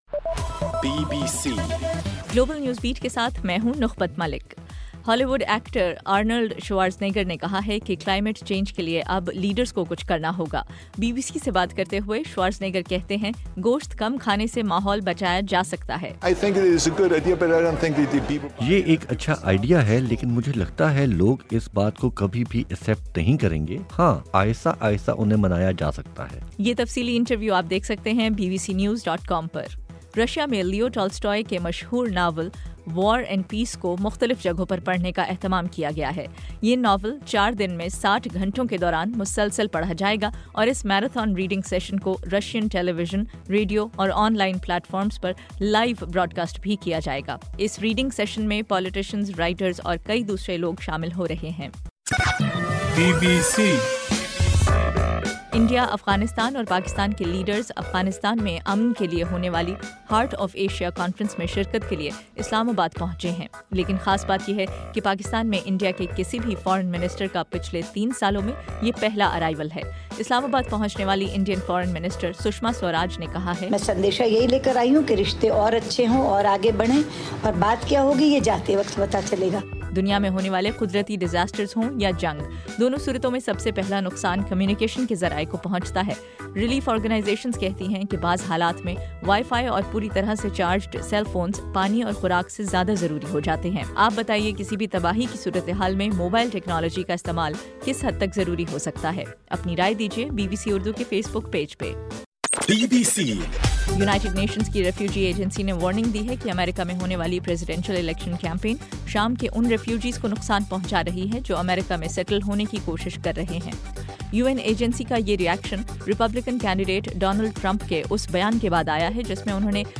دسمبر 8: رات 11 بجے کا گلوبل نیوز بیٹ بُلیٹن